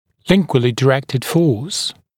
[‘lɪŋgwəlɪ dɪ’rektɪd fɔːs][‘лингуэли ди’рэктид фо:с]лингвально направленная сила